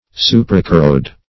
Search Result for " suprachoroid" : The Collaborative International Dictionary of English v.0.48: Suprachoroid \Su`pra*cho"roid\, Suprachoroidal \Su`pra*cho*roid"al\, a. (Anat.) Situated above the choroid; -- applied to the layer of the choroid coat of the eyeball next to the sclerotic.